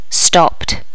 Additional sounds, some clean up but still need to do click removal on the majority.
stopped.wav